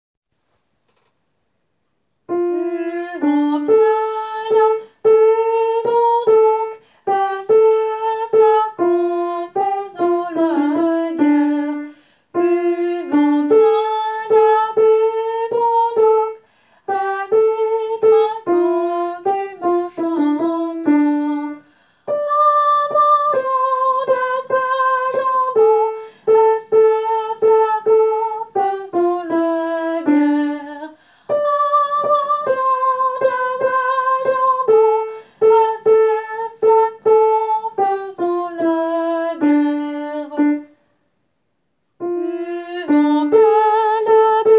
Tenor :
tourdion-tenor.wav